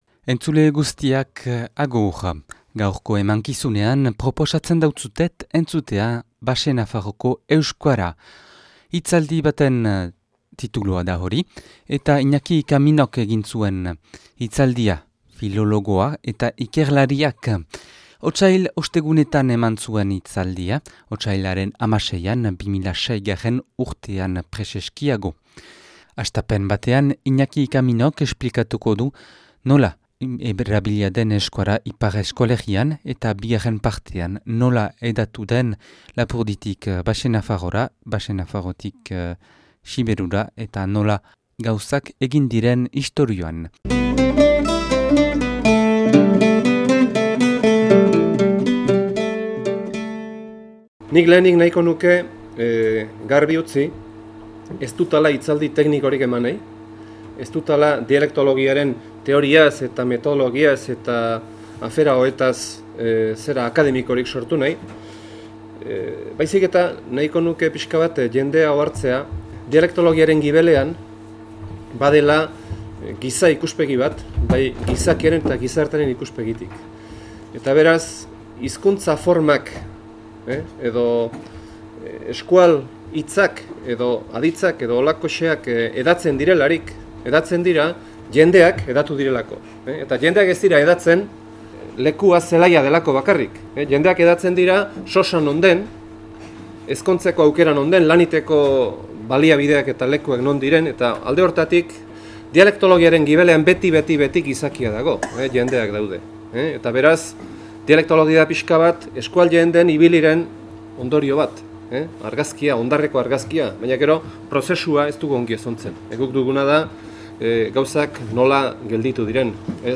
(2006. Otsailaren 16an grabatua Otsail Ostegunetan Donapaleun).